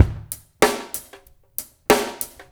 ABO DRUMS2-L.wav